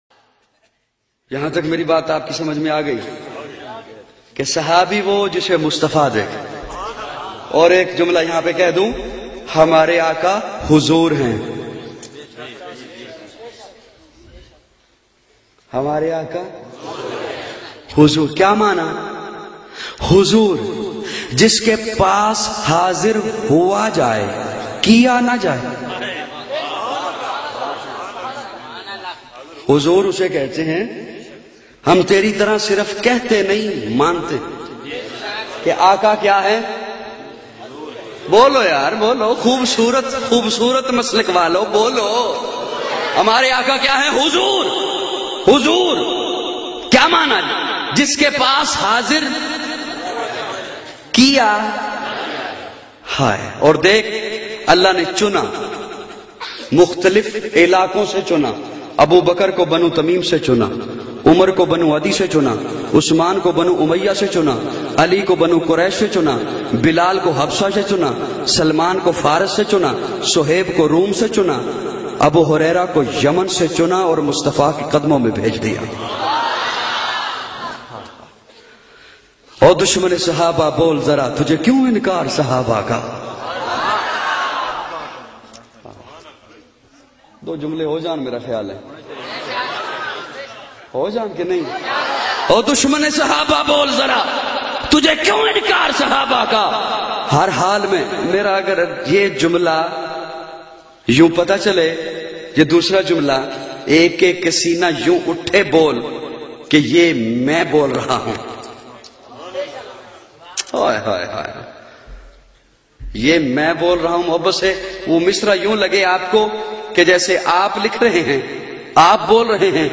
Meri Jaan Sahabah Kay Haq Mein bayan mp3